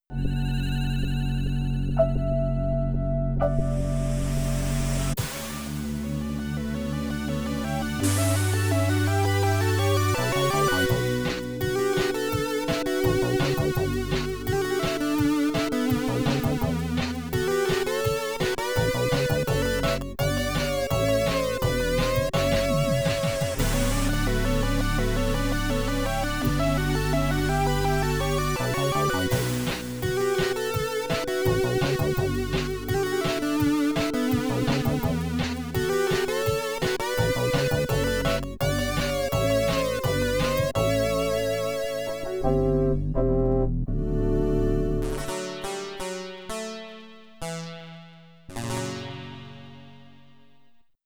Commodore 64 SID rendition